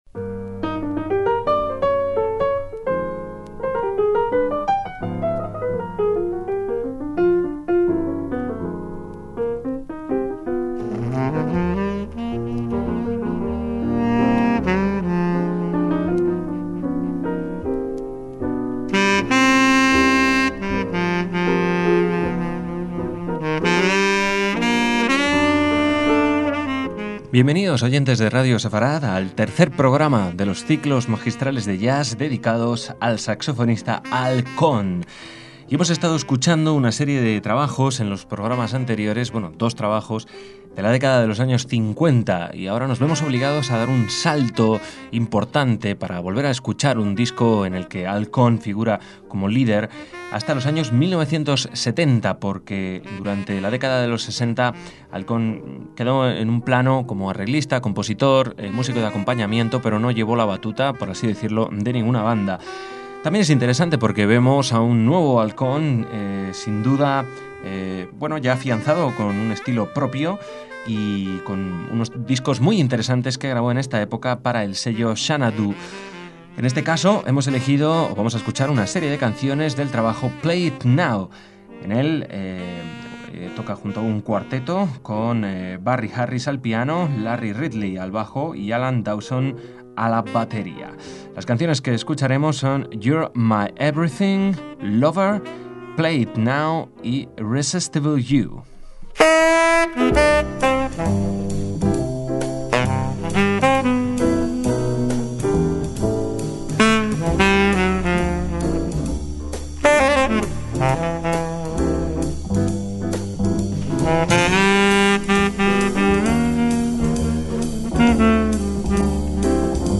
CICLOS MAGISTRALES DE JAZZ